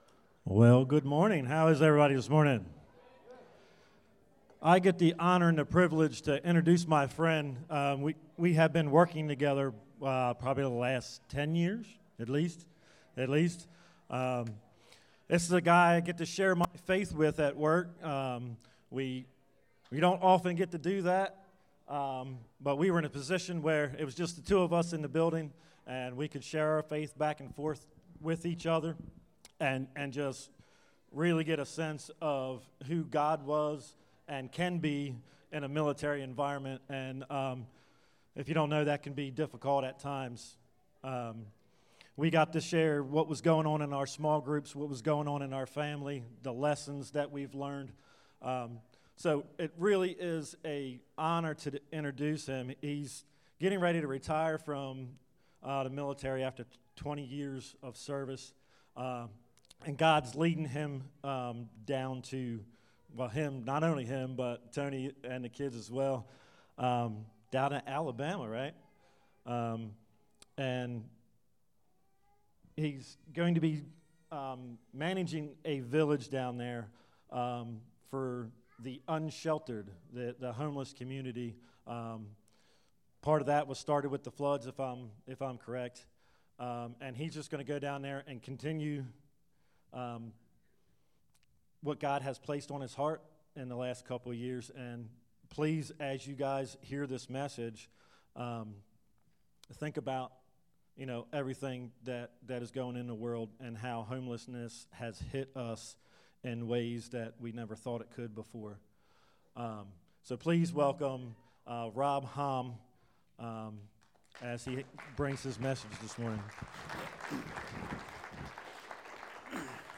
Guest Missionary Speaker